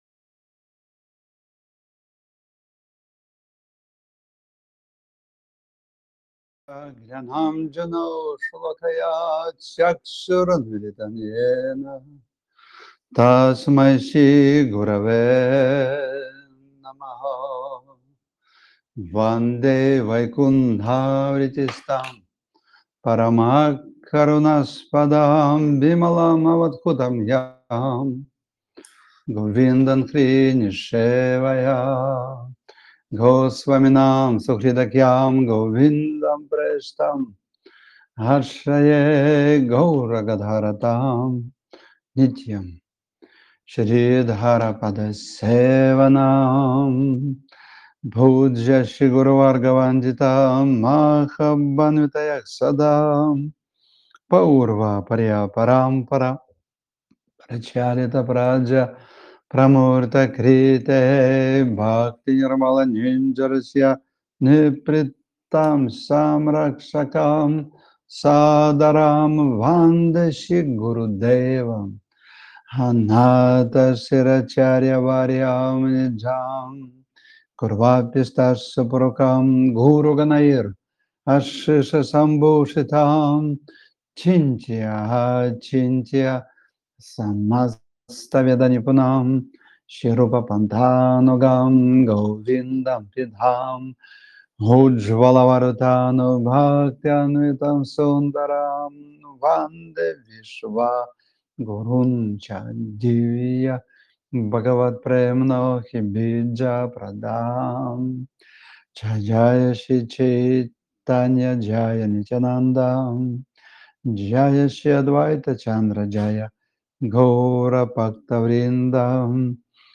Балашиха, Кучино, санньяса-ванапрастха-брахмачари ашрам
Лекции полностью